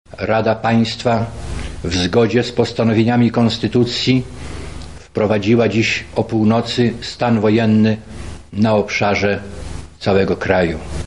Te słowa Wojciecha Jaruzelskiego można było usłyszeć dokładnie o północy 13 grudnia 1981 roku.
Jaruzelski-st-woj.mp3